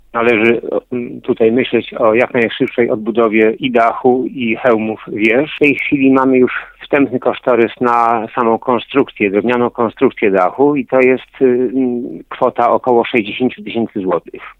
Mówił w porannym magazynie Sambor Gawiński kujawsko-pomorski wojewódzki konserwator zabytków.